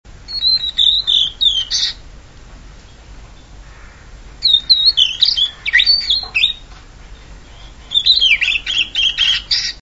さえずりのコーナー（オオルリ編）
一声 39KB とりあえず、ゲット出来た一声です。